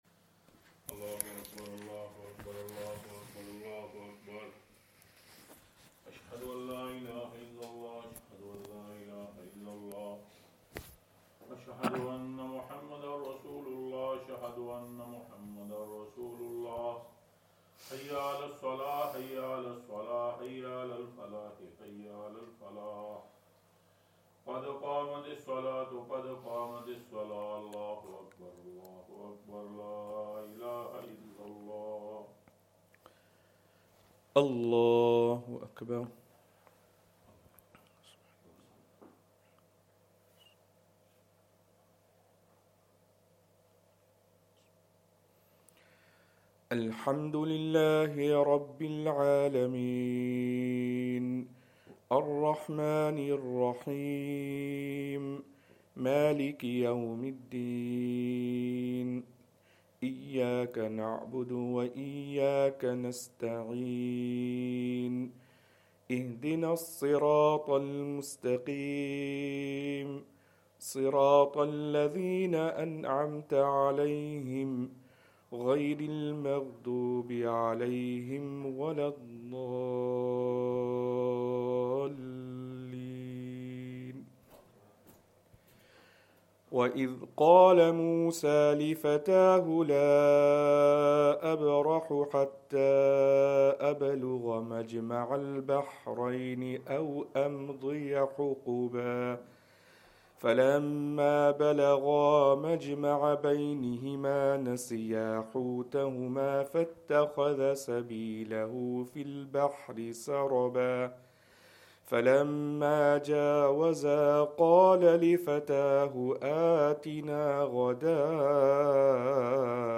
Fajr
Madni Masjid, Langside Road, Glasgow